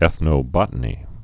(ĕthnō-bŏtn-ē)